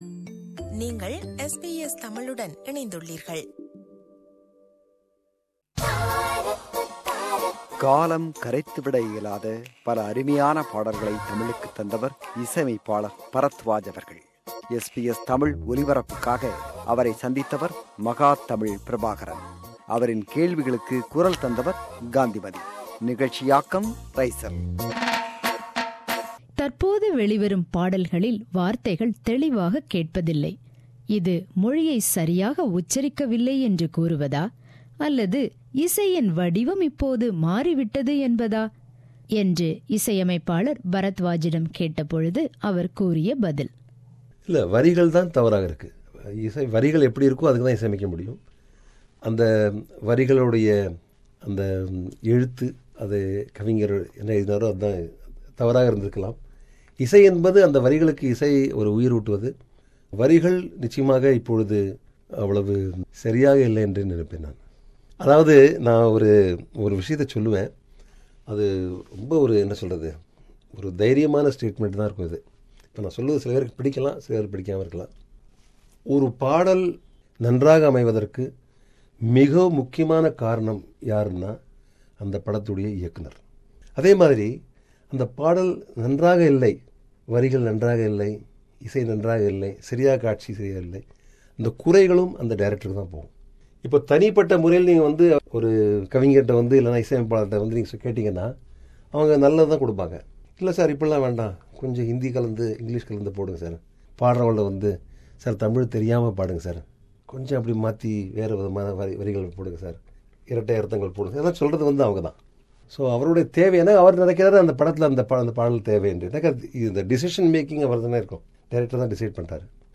Interview with Music Director Barathwaj – Part 3